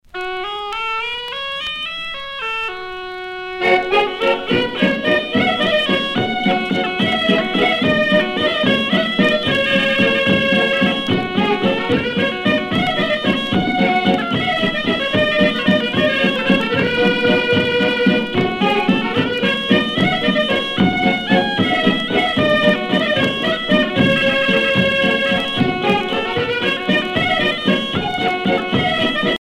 valse musette